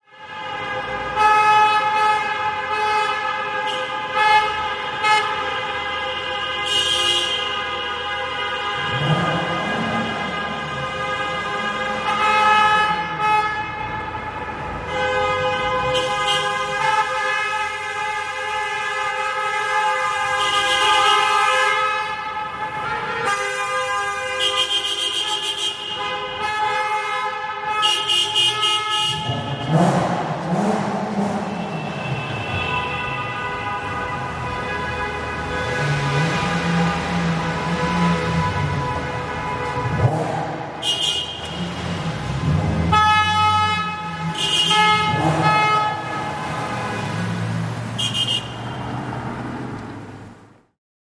На этой странице собраны звуки автомобильных пробок — гудки машин, шум двигателей, переговоры водителей.
Гул пробки в туннеле, машины стоят, гудят друг на друга